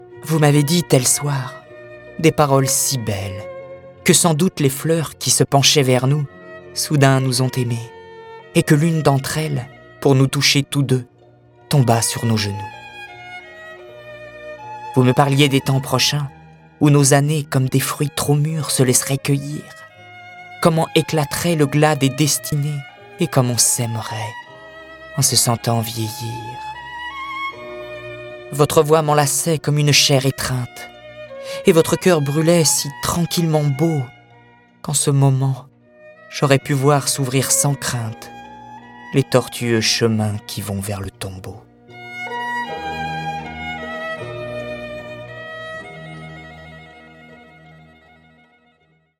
Extrait gratuit - Mariage les plus beaux textes de Collectif
Les textes sont illustrés avec les musiques ...